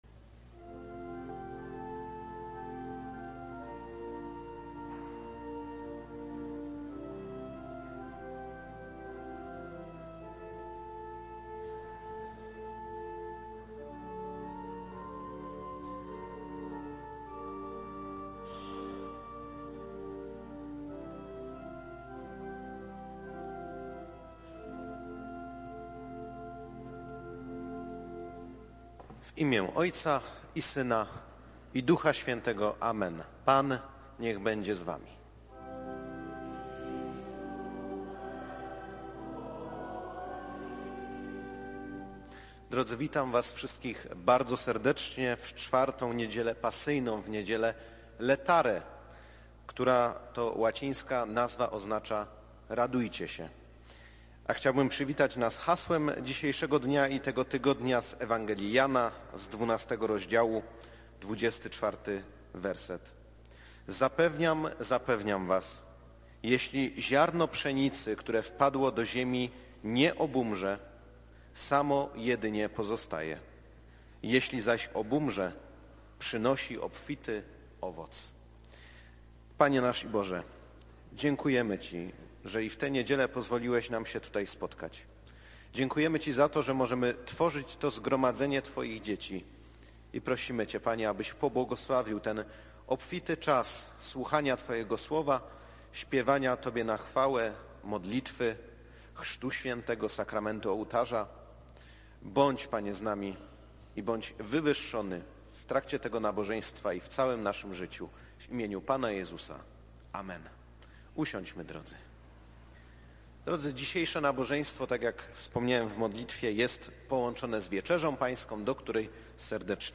2 NIEDZIELA PO TRÓJCY ŚWIĘTEJ